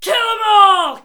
battle-cry-3.mp3